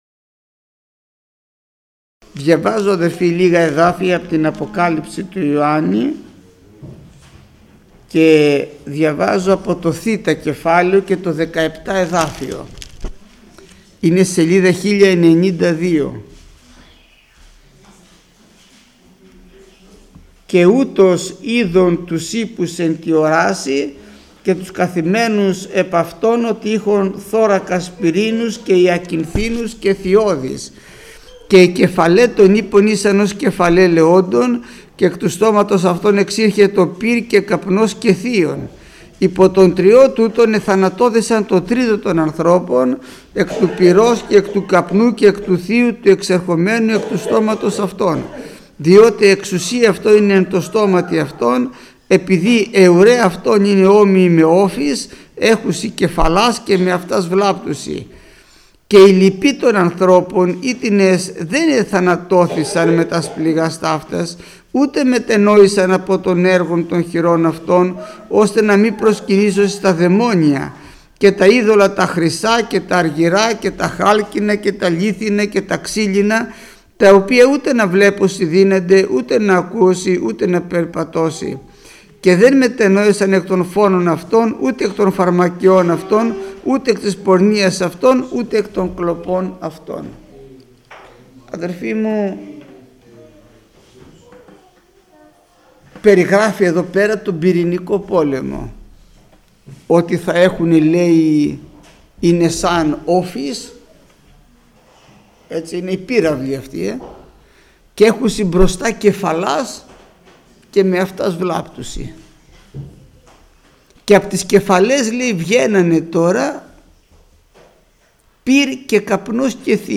Μήνυμα πριν τη θεία κοινωνία